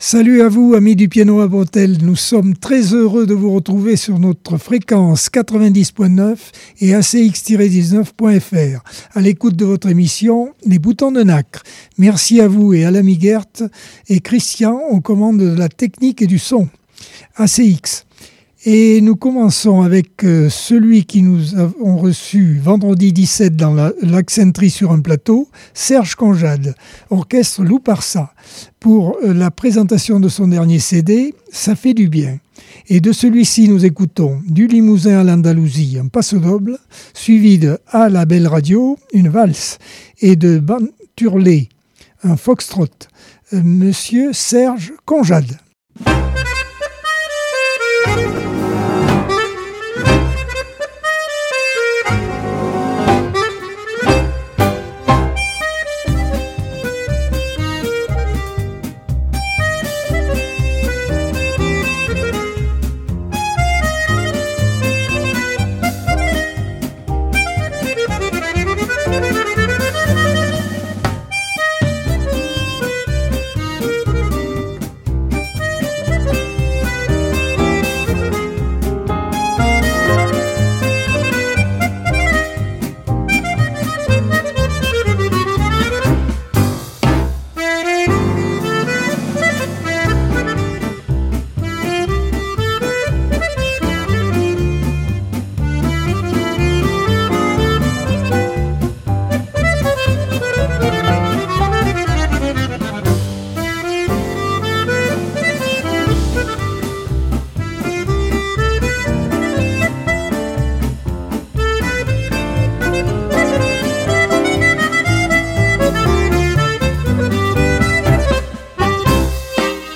Accordeon 2025 sem 06 bloc 1 - Radio ACX